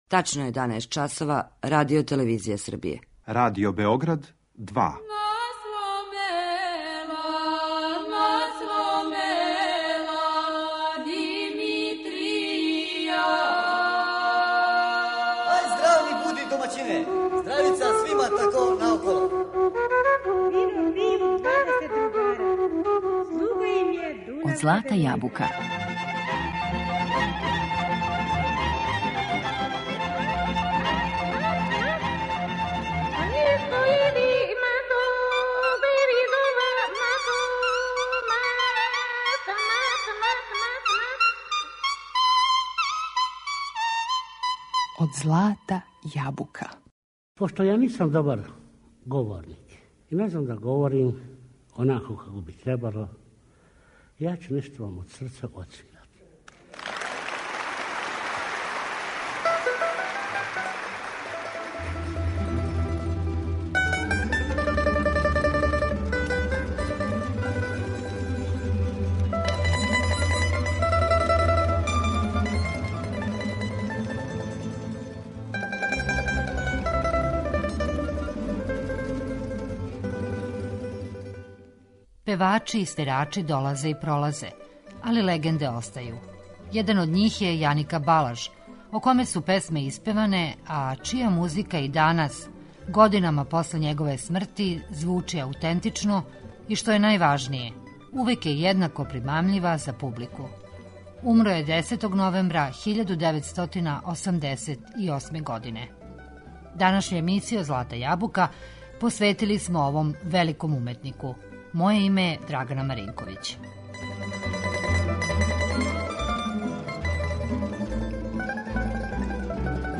Његова музика и данас, годинама после његове смрти, звучи аутентично и, што је најважније, увек је једнако примамљива за публику.
Међу хиљадама тамбурица увек је лако препознати тамбуру Јанике Балажа.